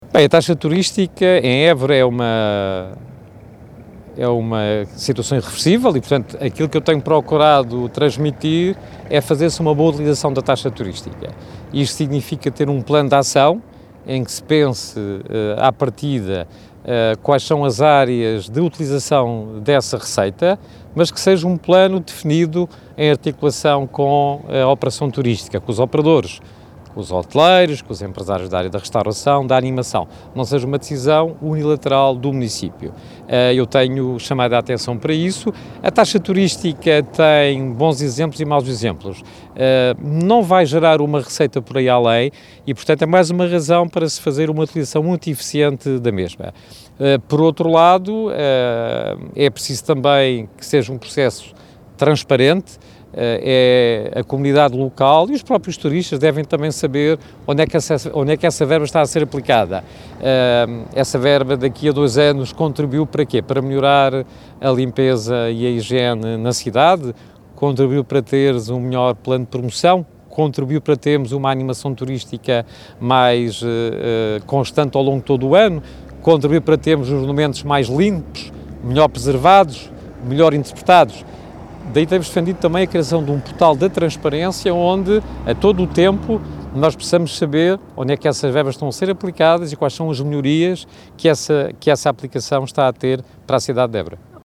Taxa turística avança em Évora. ERT fala em coisas boas e más.(entrevista)